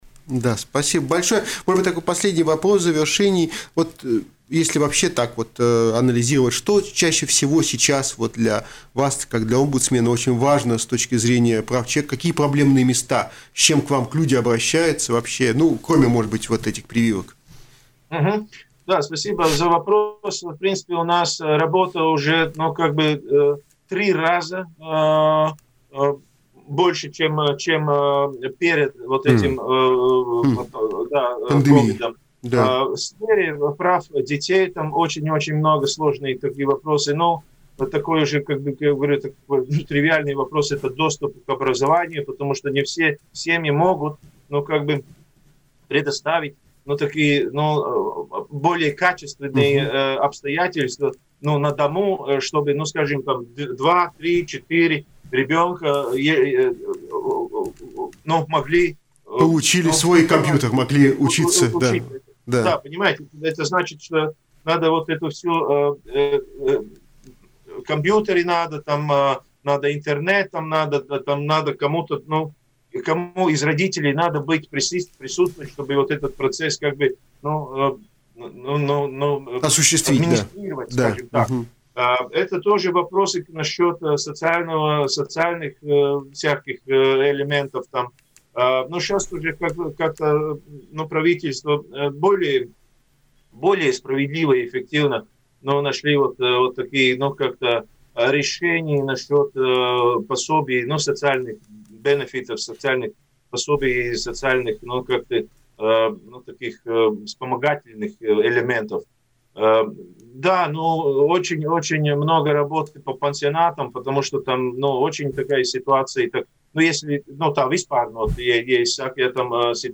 После начала пандемии COVID-19 объем работы омбудсмена в Латвии вырос в три раза, рассказал в эфире радио Baltkom омбудсмен Латвийской Республики Юрис Янсонс.